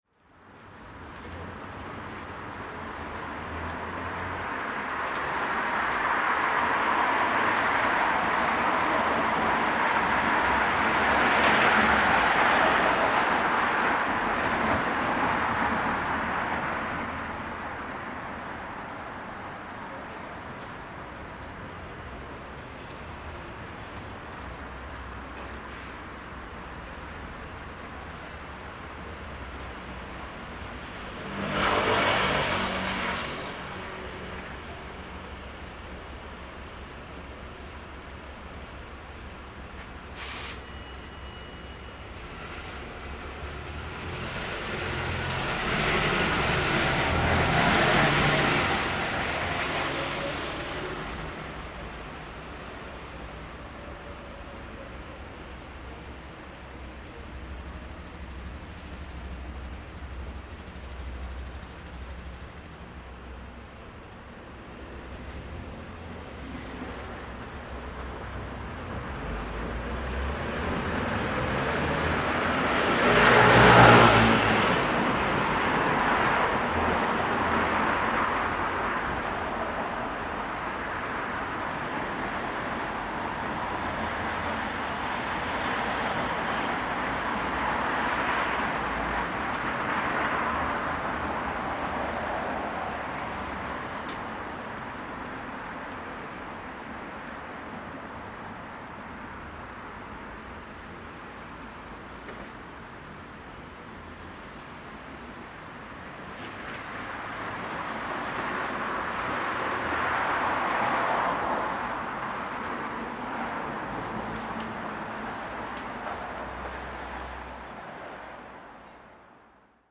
Recording in front of Laverys Bar which is closed. Space is much quieter, however, there is still some movement from pedestrians and vehicles. Beginning of Lockdown 2 in Belfast.